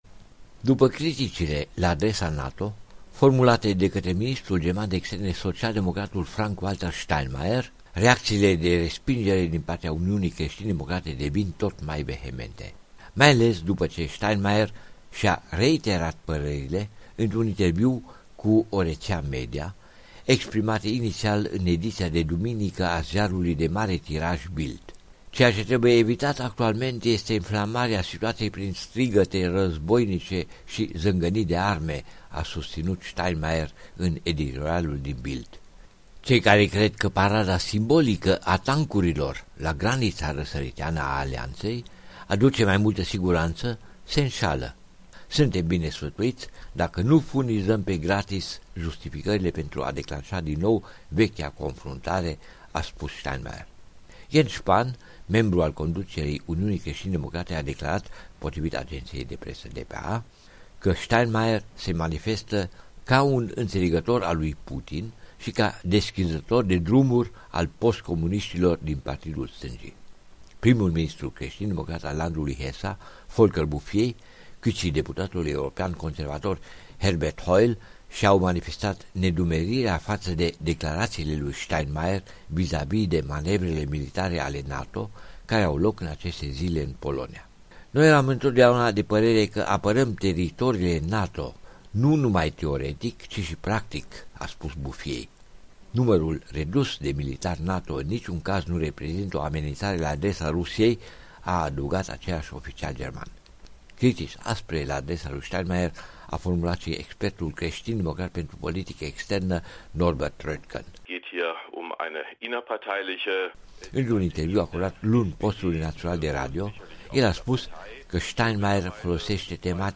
Corespondenţa zilei de la Berlin